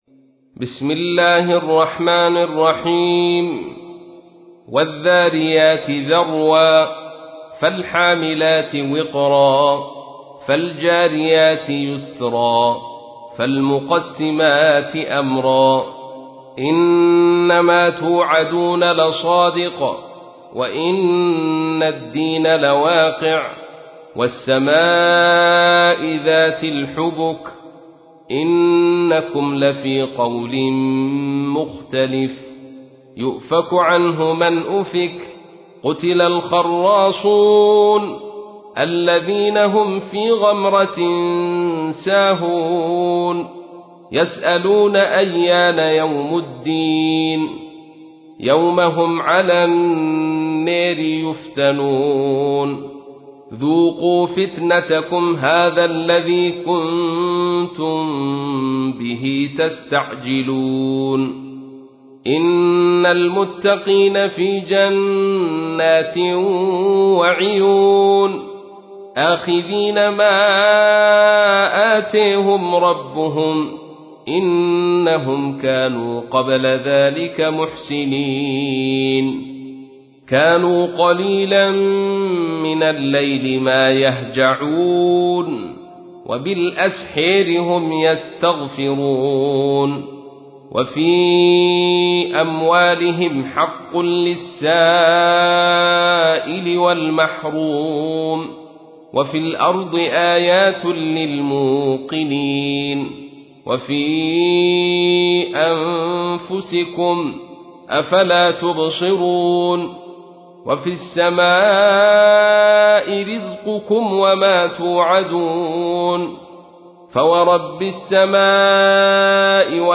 تحميل : 51. سورة الذاريات / القارئ عبد الرشيد صوفي / القرآن الكريم / موقع يا حسين